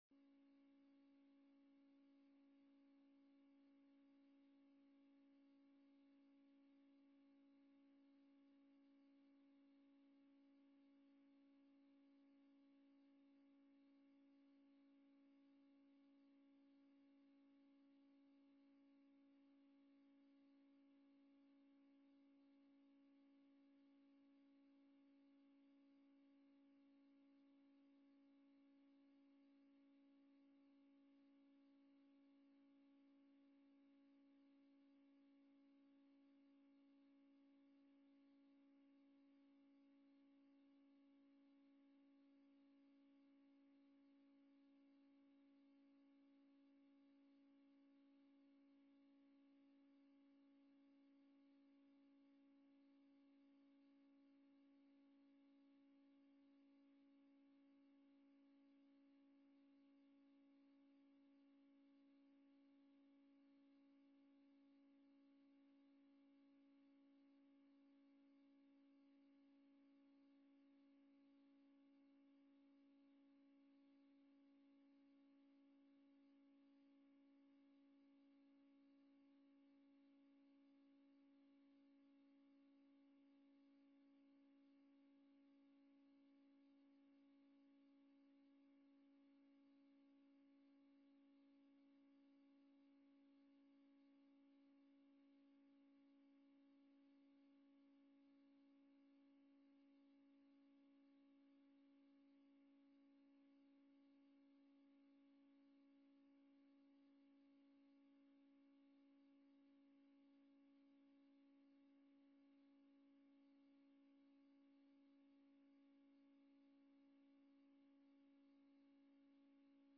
Agenda Nieuwegein - Raadsvergadering Digitaal vanuit raadzaal donderdag 12 november 2020 20:00 - 23:00 - iBabs Publieksportaal